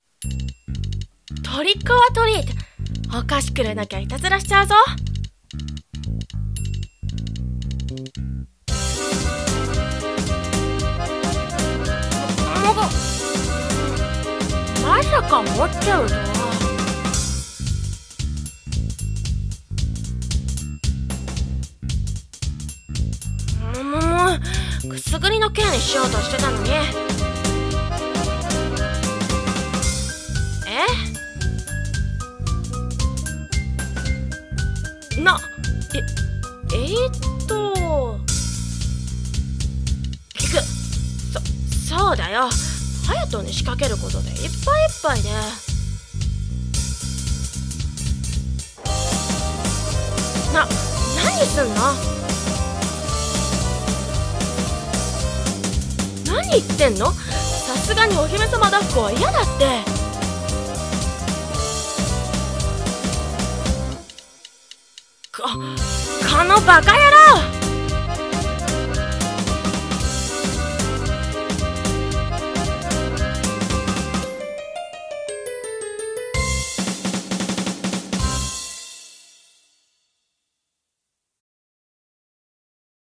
【ハロウィン声劇】シンデレラと王子様〈コラボ募集中〉